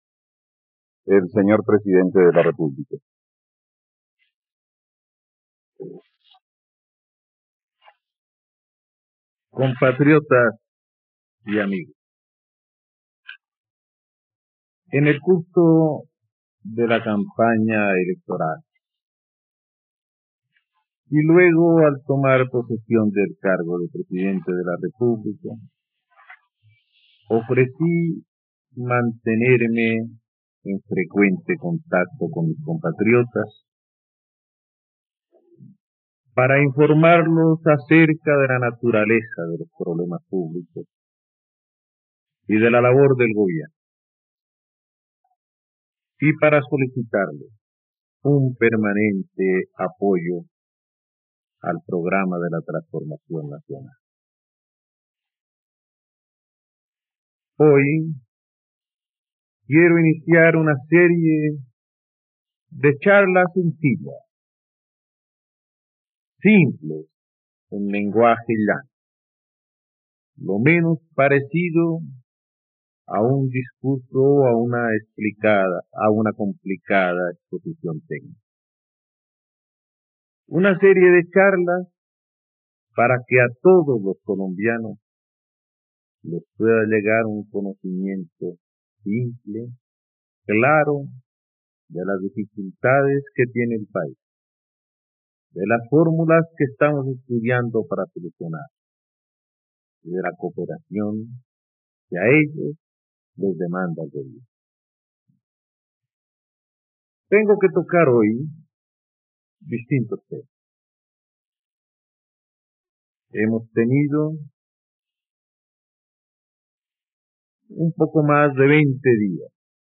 ..Escucha ahora el discurso de Carlos Lleras Restrepo sobre la reforma constitucional, violencia, universidades públicas y devaluación monetaria en RTVCPlay.